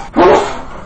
sneeze2.ogg